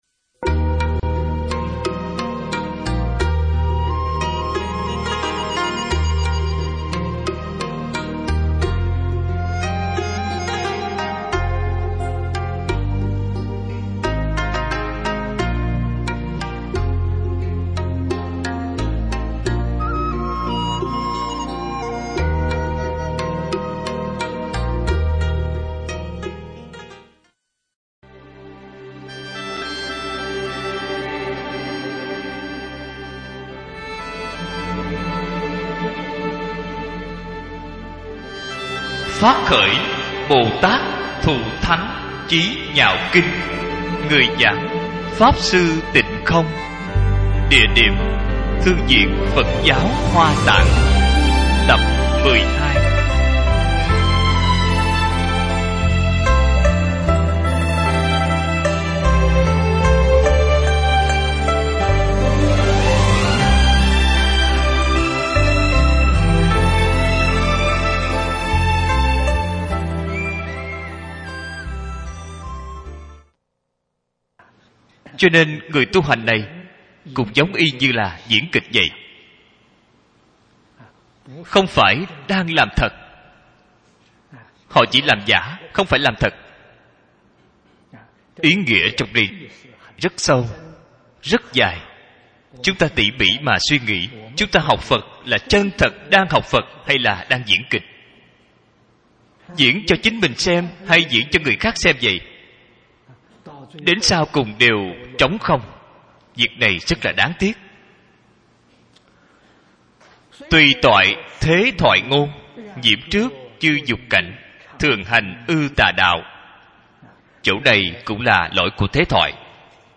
Bồ Tát Chí Nhạo Kinh - Tập 9 - Bài giảng Video